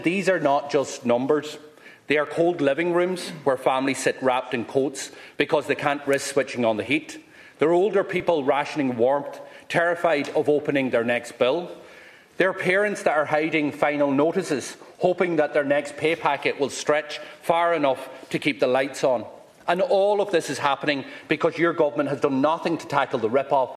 Sinn Fein TD Pearse Doherty says it’s very alarming………..